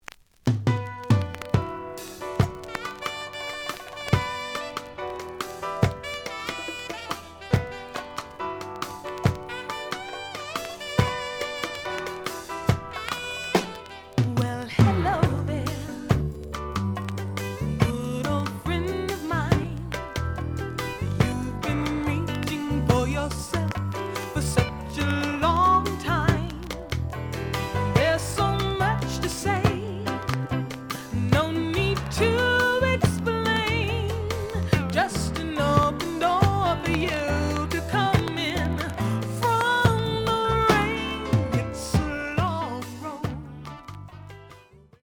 The audio sample is recorded from the actual item.
●Genre: Disco
Some click noise on both sides.)